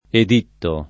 editto [ ed & tto ] s. m.